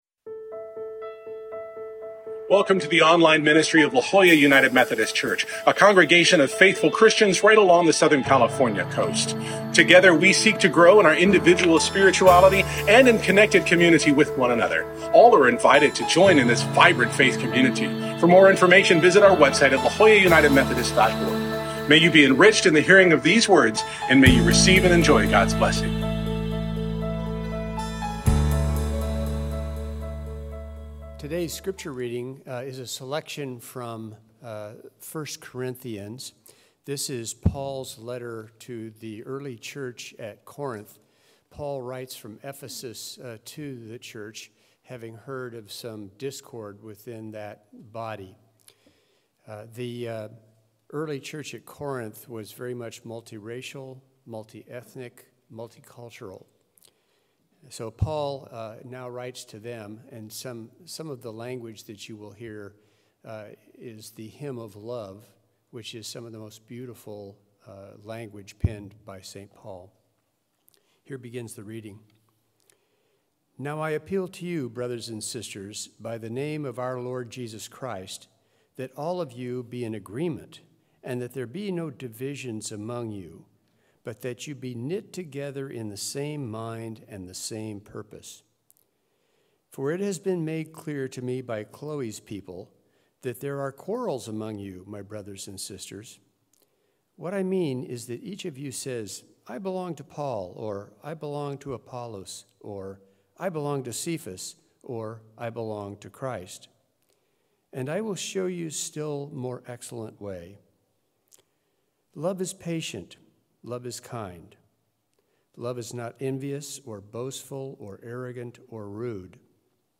We continue our post-Easter sermon series exploring “The Power of Words.” This week, we remember the common conflict-avoidance of never talking about politics or religion by exploring both!